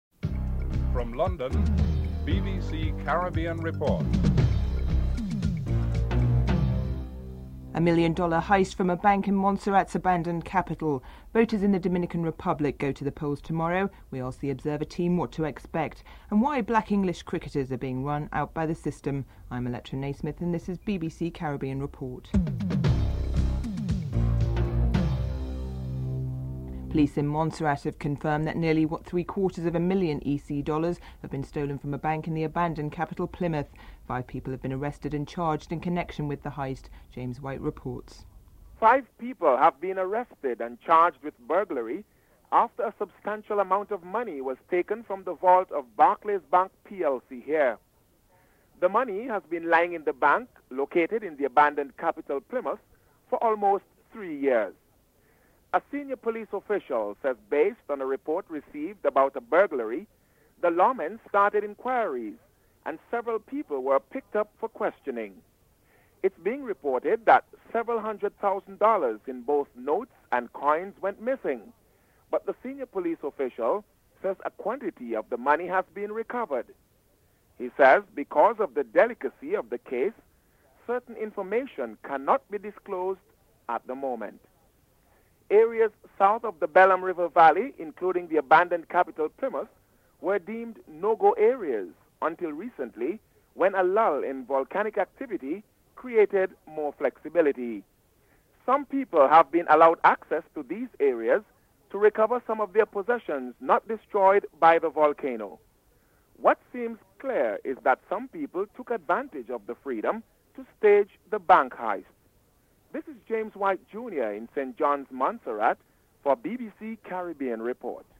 9. Recap of top stories (14:35-15:08)